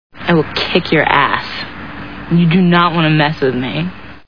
Six Feet Under TV Show Sound Bites